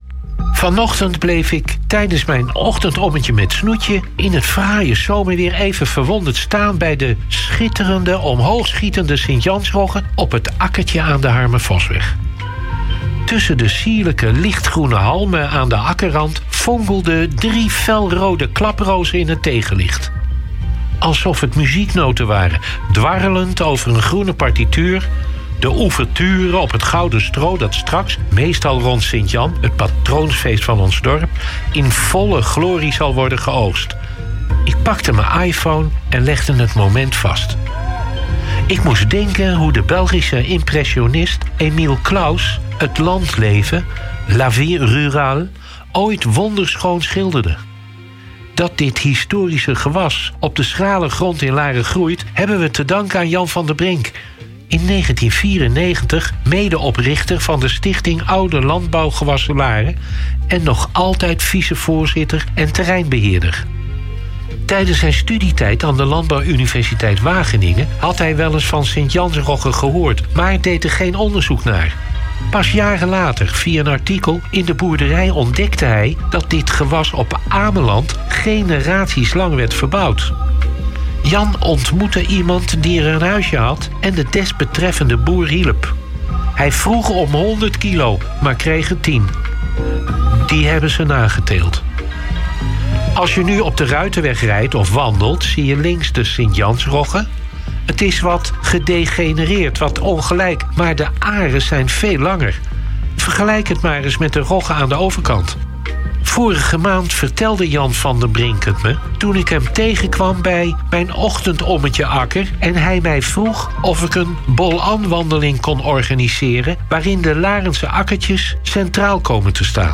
Oud-wethouder Leo Janssen van Laren wandelt, herinnert en schrijft het op in z'n columns. En wekelijks komt hij over Laren vertellen bij NH Gooi.